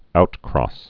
(outkrôs, -krŏs)